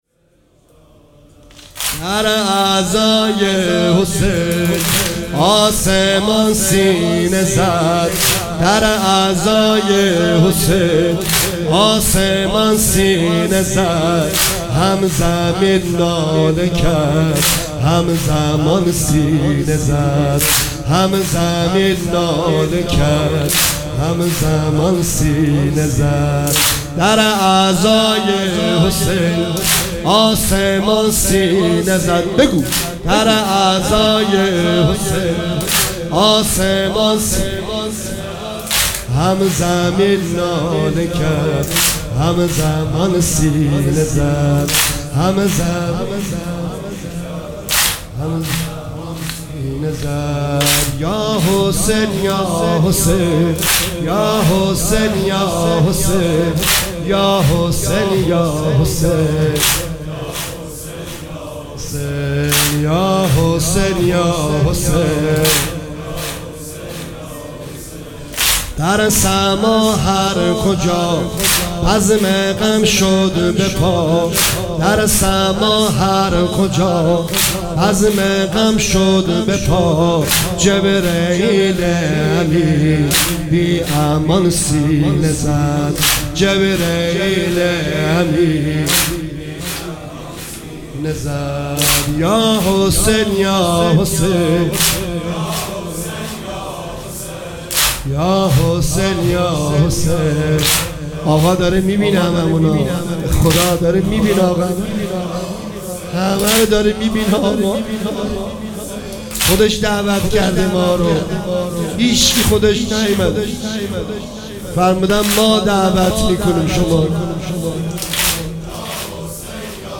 امامزاده معصوم (ع)
مداحی محرم